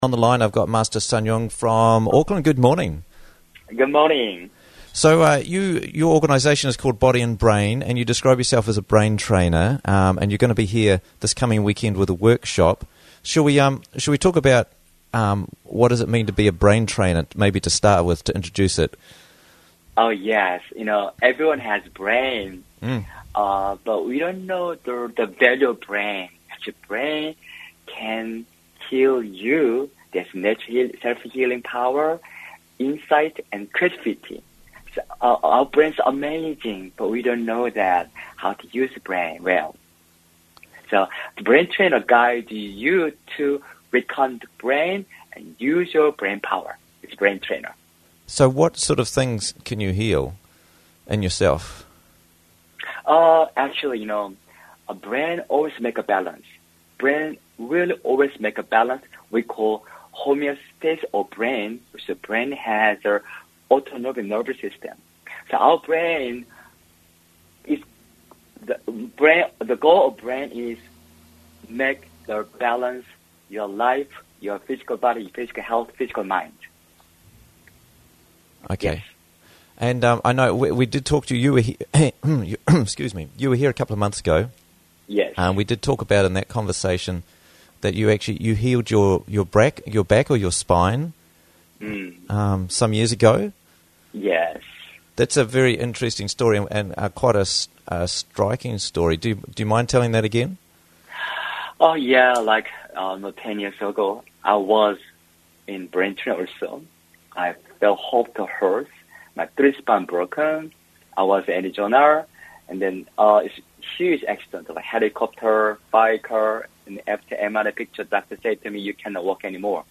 Finding True Self Workshop - Interviews from the Raglan Morning Show